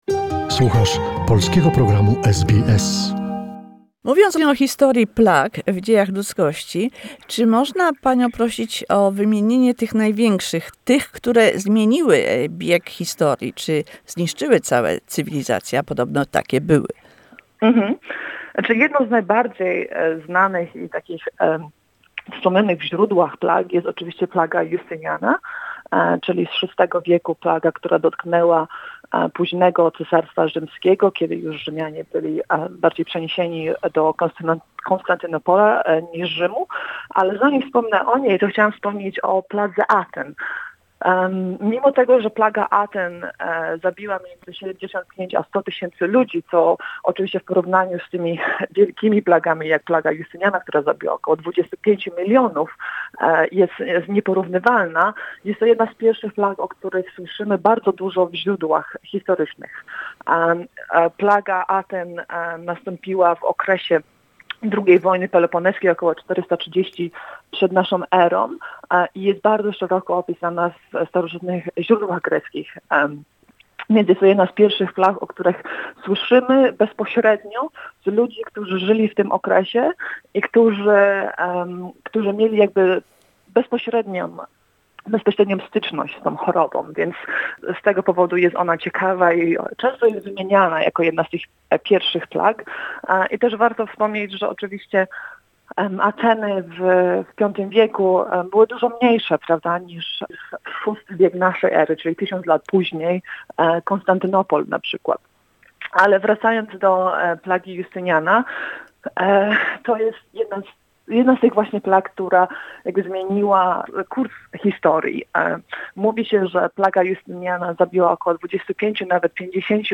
Second part of the conversation.